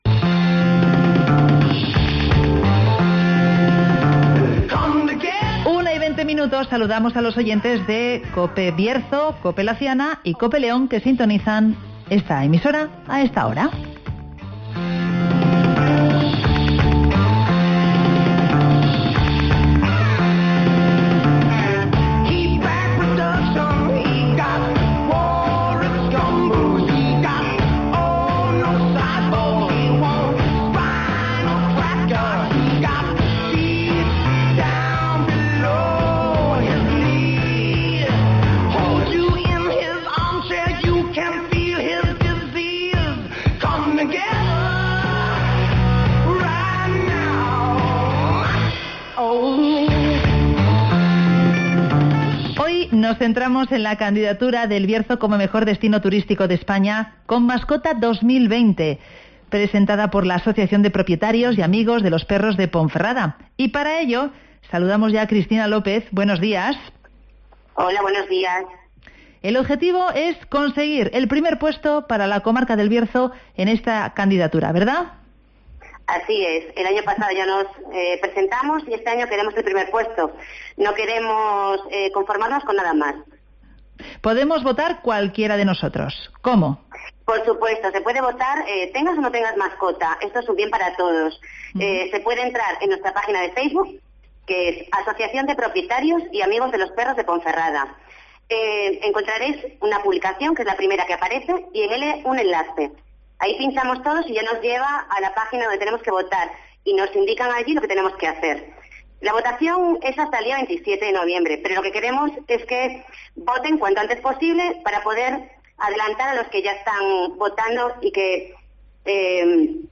El Bierzo presenta su candidatura como mejor destino turístico con mascota de España (Entrevista